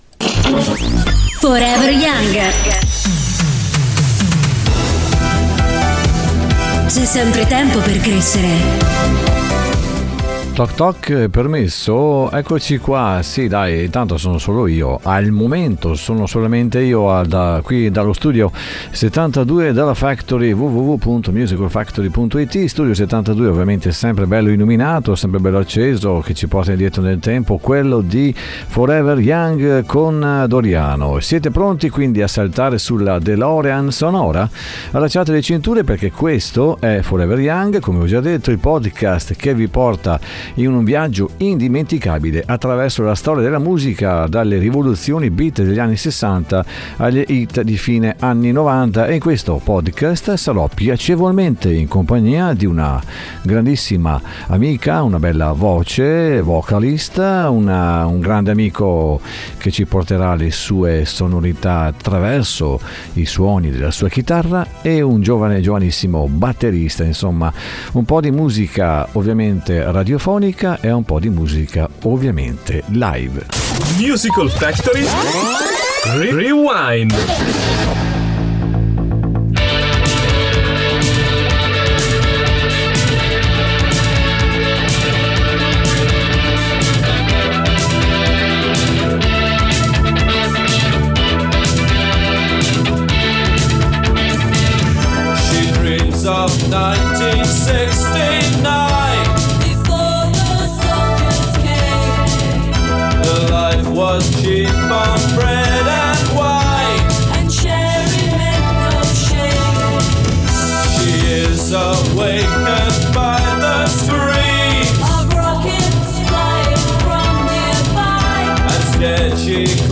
condita da qualche performance live con alcuni amici cantanti e musicisti